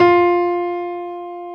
55p-pno21-F3.wav